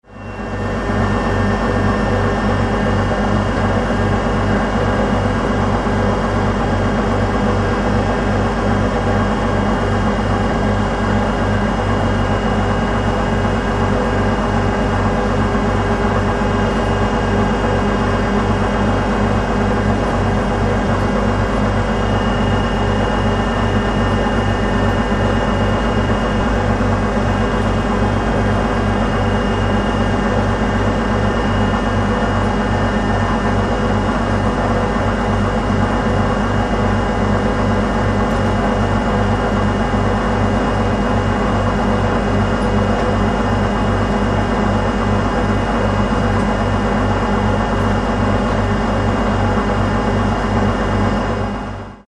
Звуки военной техники